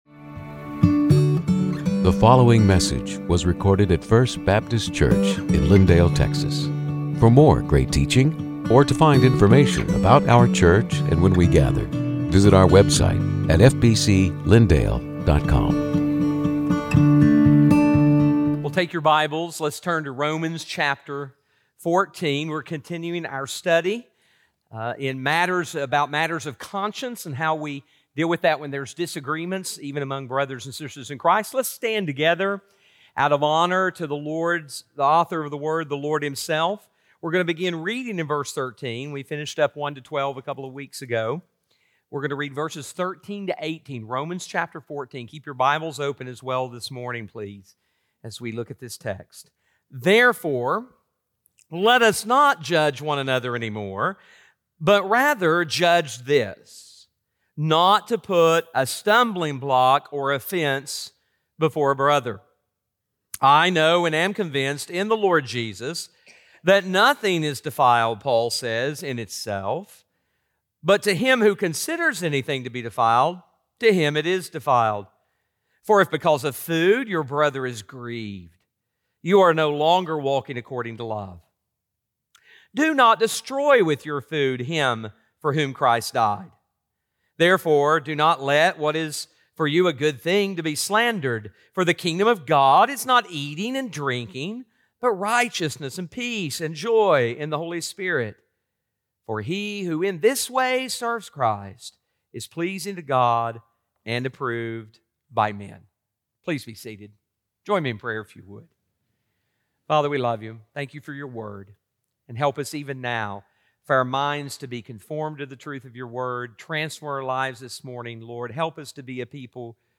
Sermons › Romans 14:13-18